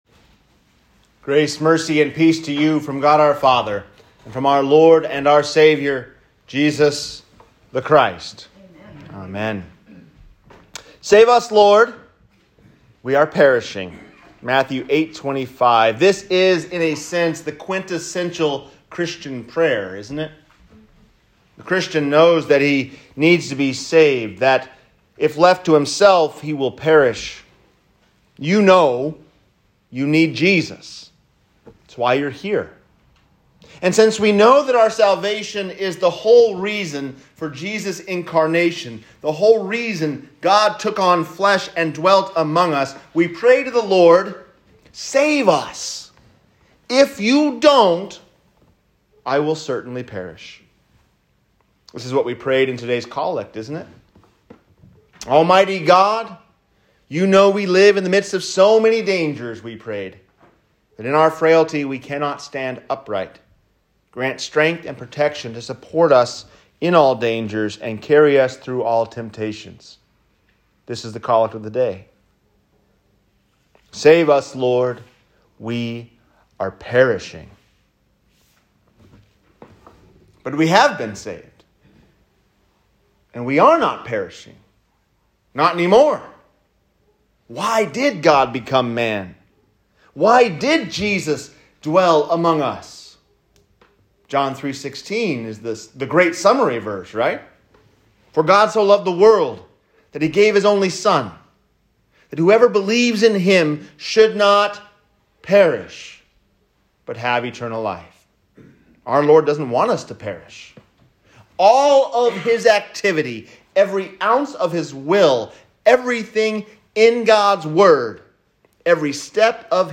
The Lord Has Saved Us. We Are Not Perishing | Sermon